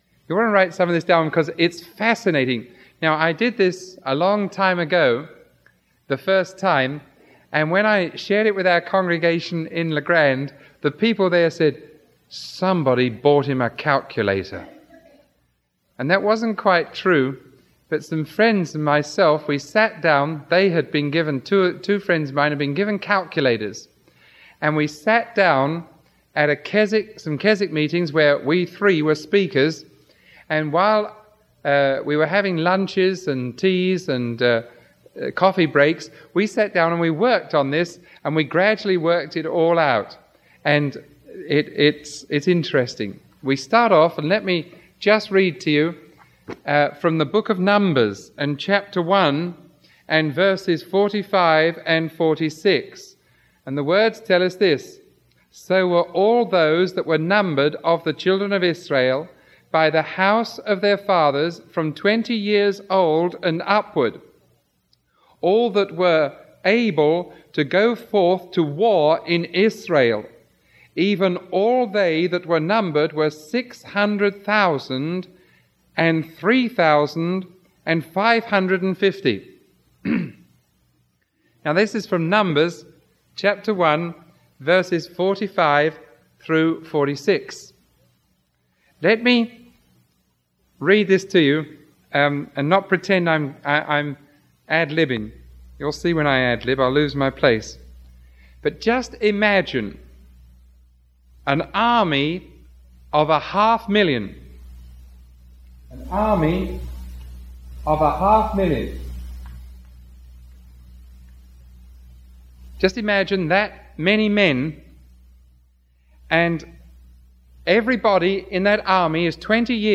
Sermon 0027AB recorded on February 28, 1979 teaching from Numbers 1:45-46 – The Nation of Israel.